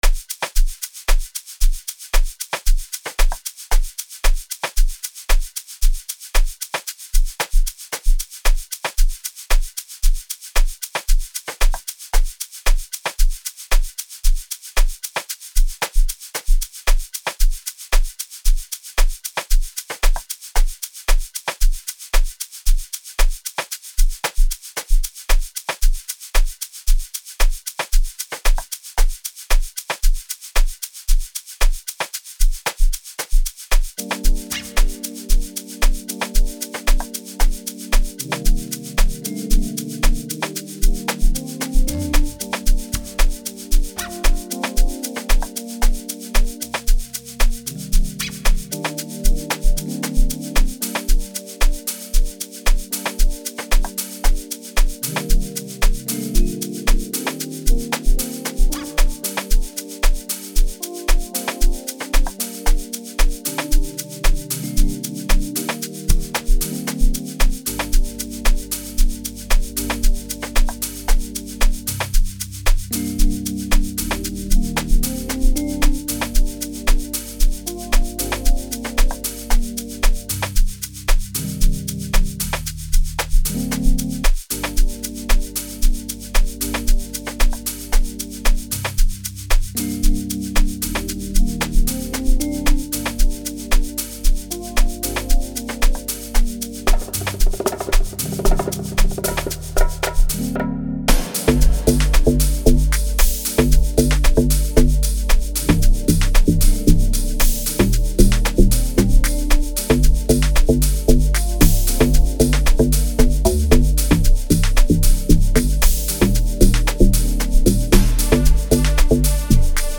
05:54 Genre : Amapiano Size